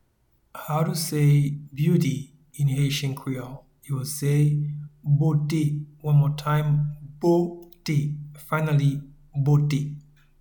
Pronunciation and Transcript:
Beauty-in-Haitian-Creole-Bote.mp3